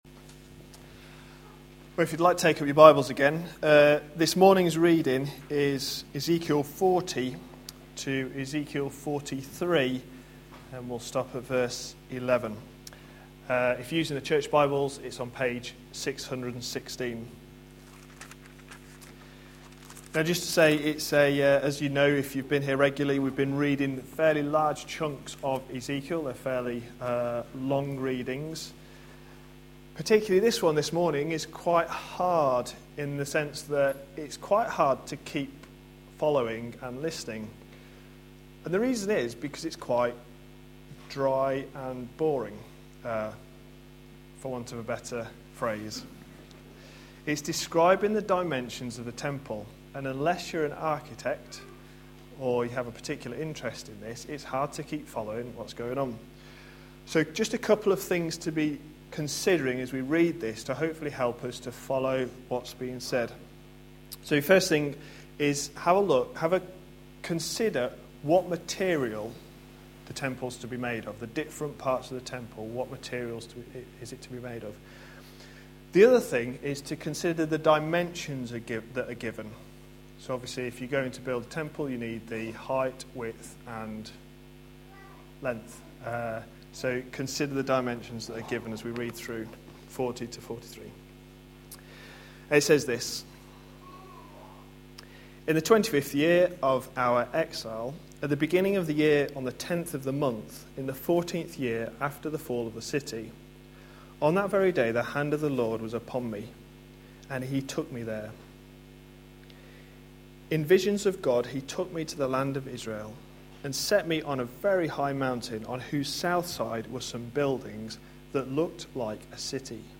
A sermon preached on 17th August, 2014, as part of our Ezekiel series.